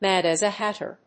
/ˌmæd æz ə ˈhætə(米国英語)/
アクセント(as) mád as a hátter